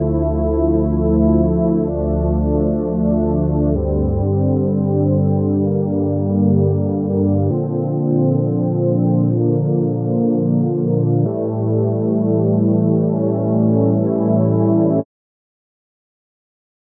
Ищу VST pad как в грустной попсе
подскажите pad, звучащий ненасыщенно, типичный такой медленно-попсовый, атмосфера тихого поля в тумане, аля Дмитрий Колдун/Юлия Савичева (ссылку не дают вставить), все, что пробовал, звучат как-то плотно и пластмассово (например, omnisphere), или я не умею готовить, просьба сильно не кидаться, я...